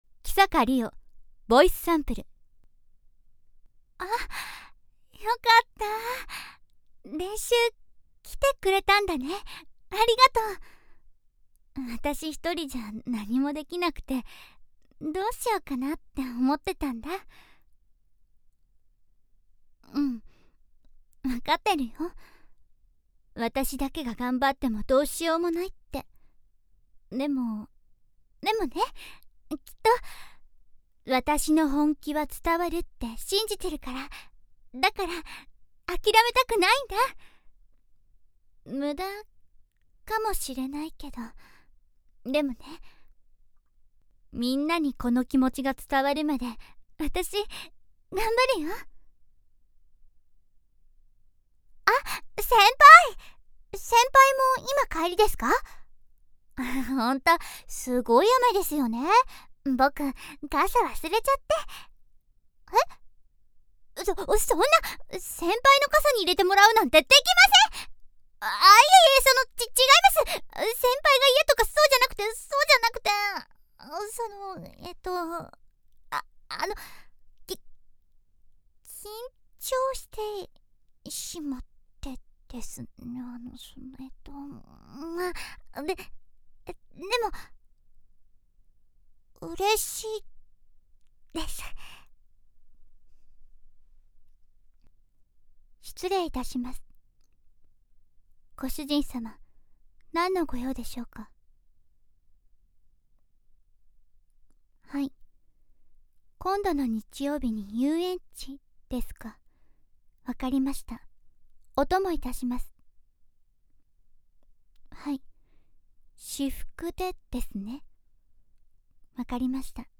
＜サンプルヴォイス＞
[ MP3をDL ]   ■性別：女性　■誕生日：11月5日
■方言：関西弁、京都弁可能